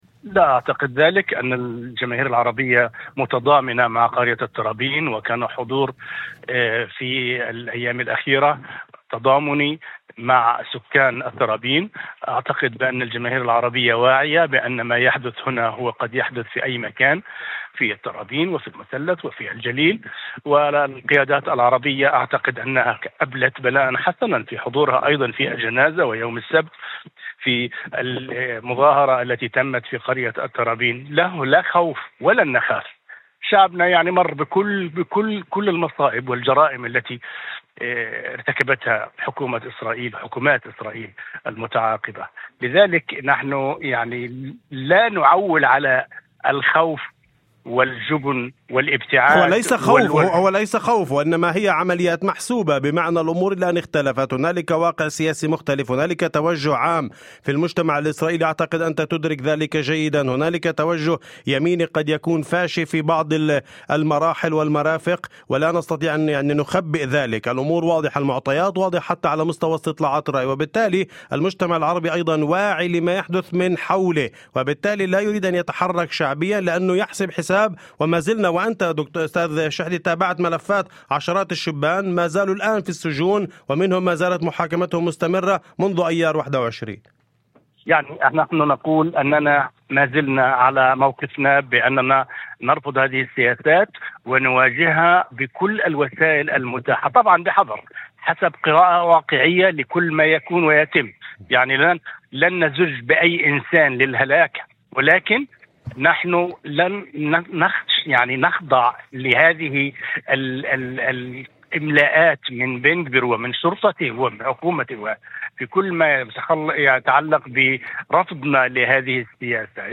وأضاف في مداخلة هاتفية لبرنامج "أول خبر"، على إذاعة الشمس، أن التضامن الواسع الذي ظهر مع سكان الترابين، سواء من خلال الحضور الشعبي أو مشاركة القيادات العربية في الجنازة والمظاهرة الاخيرة، يعكس فهما جماعيا بما يجري.